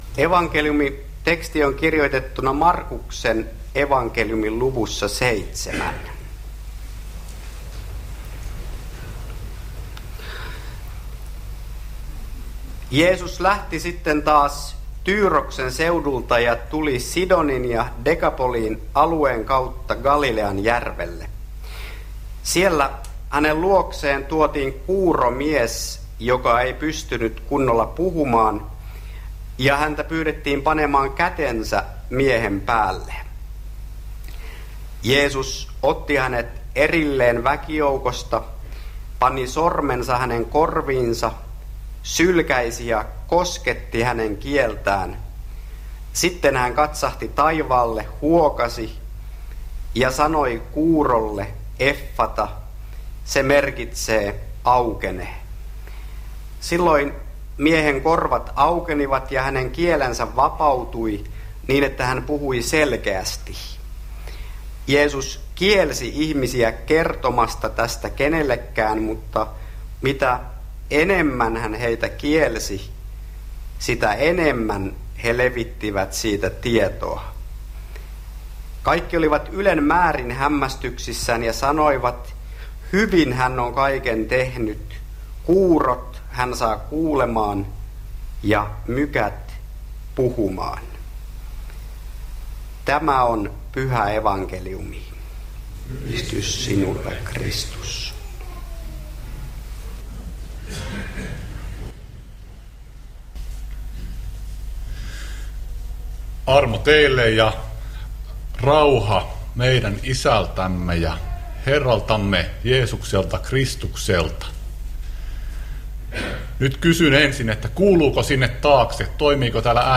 saarna